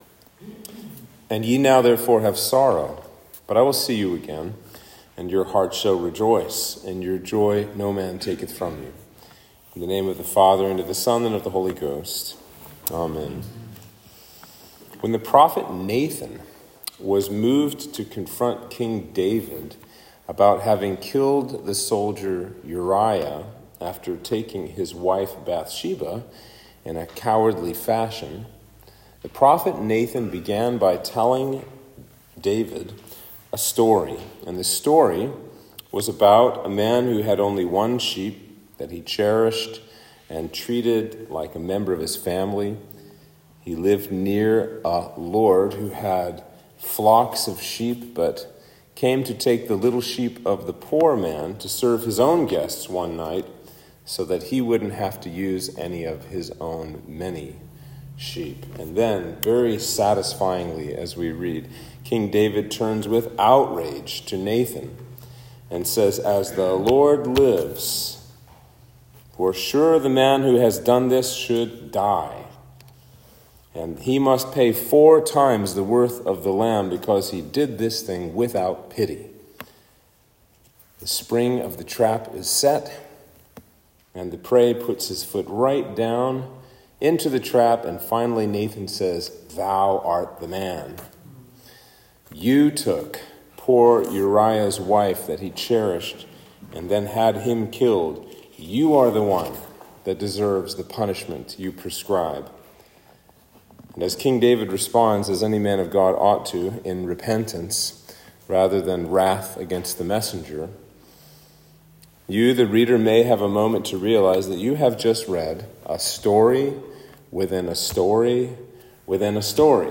Sermon for Easter 3